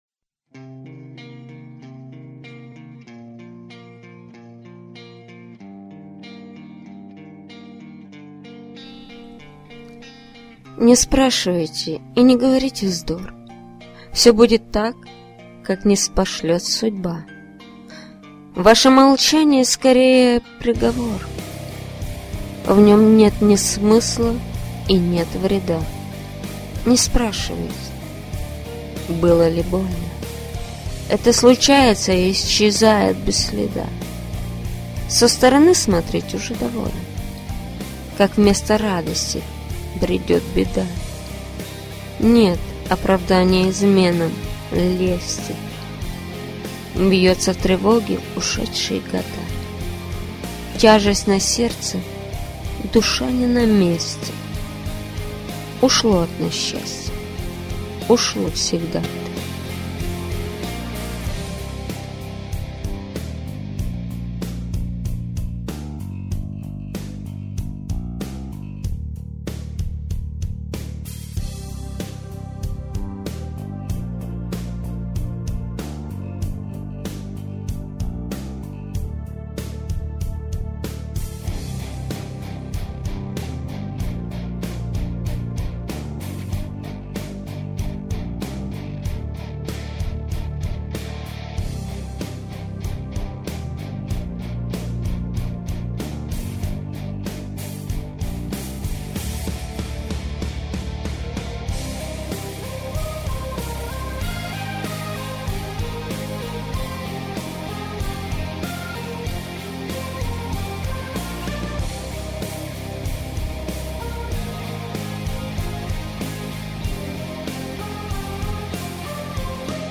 ТИП: Музика
СТИЛЬОВІ ЖАНРИ: Ліричний
ВИД ТВОРУ: Пісня